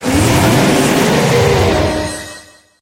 Cri d'Éthernatos dans Pokémon HOME.